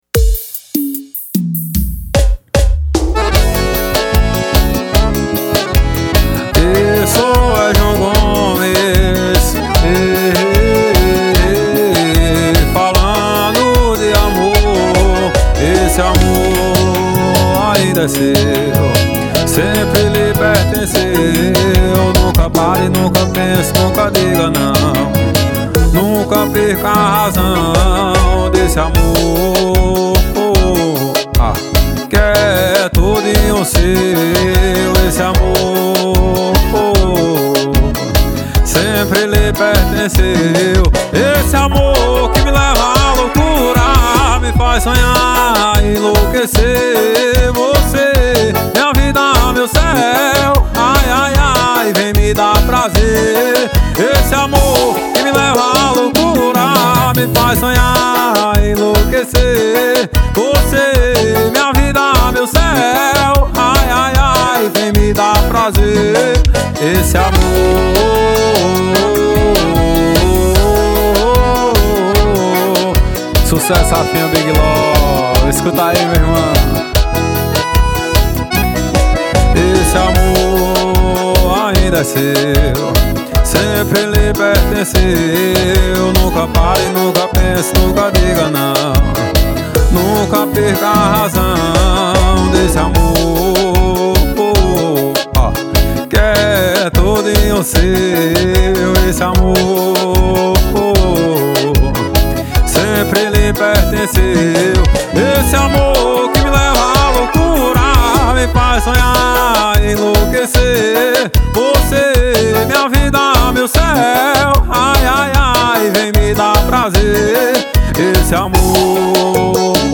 2024-02-14 17:56:55 Gênero: Forró Views